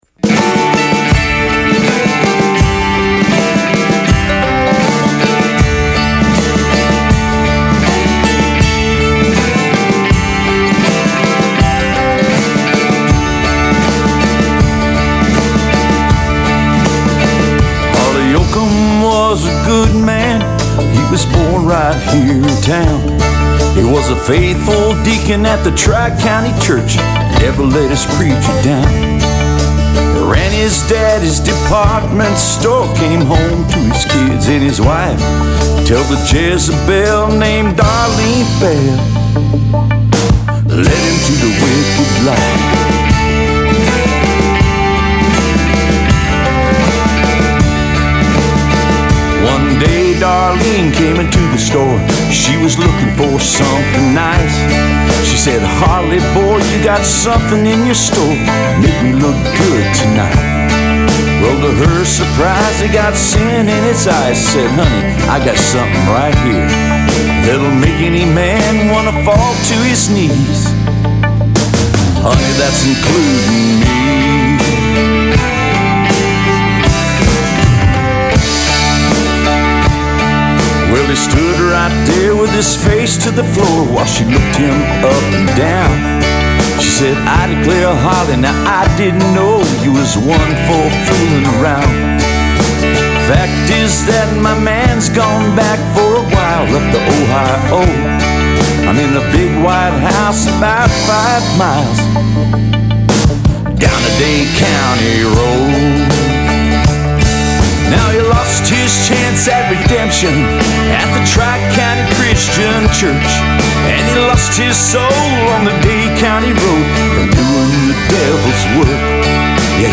One Of The Hardest Songs I Have Tried To Mix, Ideas WELCOME!!!!!
Probably one of the things that makes it hard is that it is a hybrid of several styles and textures, and getting them to sort of hang together as one thing is part of the difficulty. The other is my fault as the arranger, there are real competing frequencies between the synth jaw harp, mandolin, and acoustic guitar, with Nashville tuning. I like the dialogue between them but they are all working the same frequency range.....